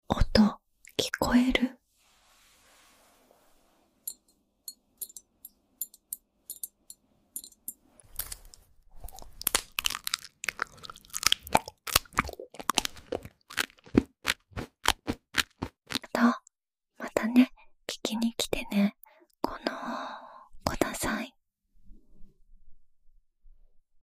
An elegant Japanese woman performs